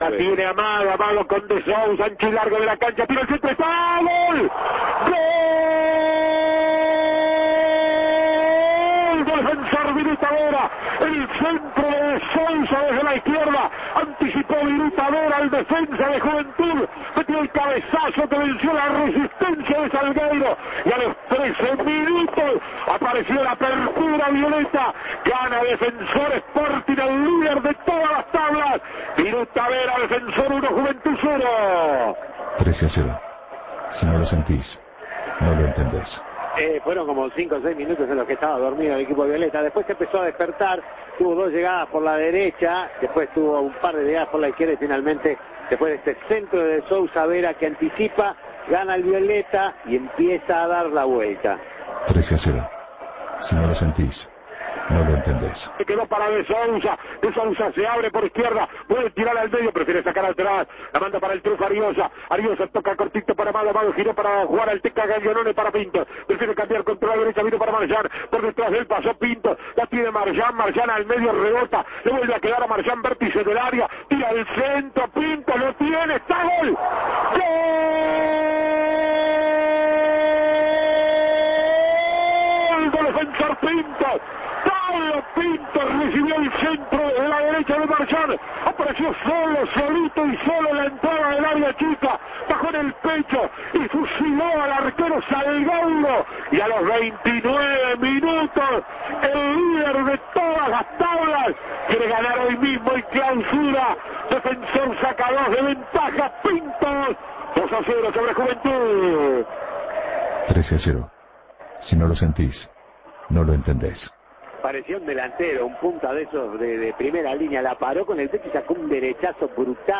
Relatos